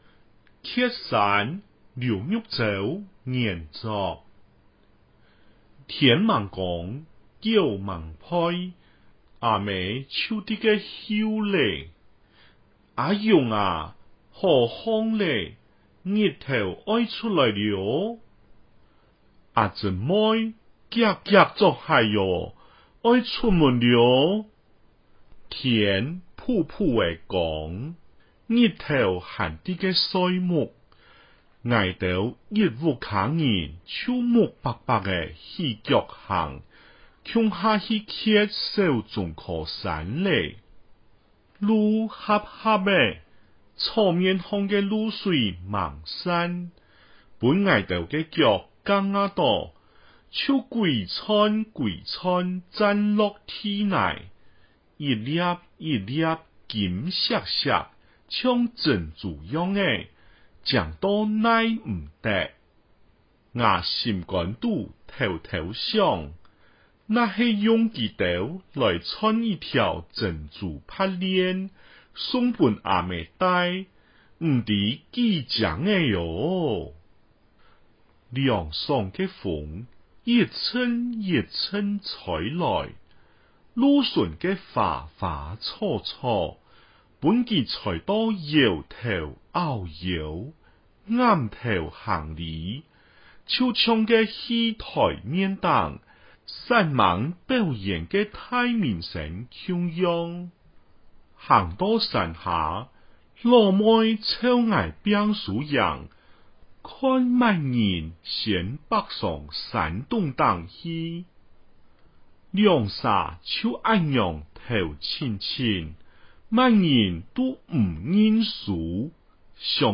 客語朗讀錄音檔(mp3檔)